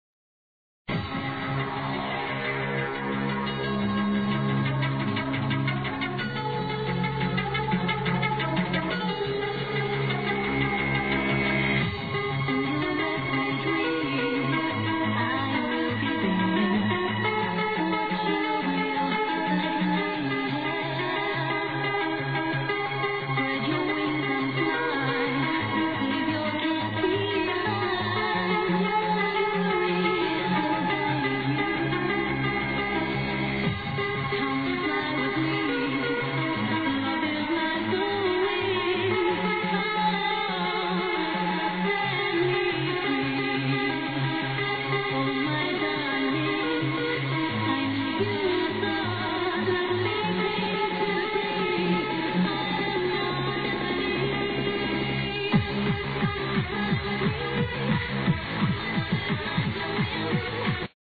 The quality isn't very good but you can listen the music.